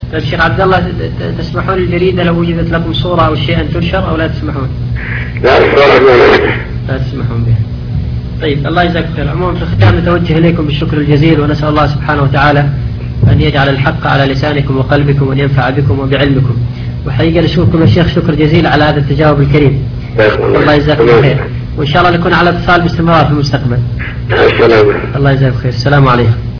تسجيلات - لقاءات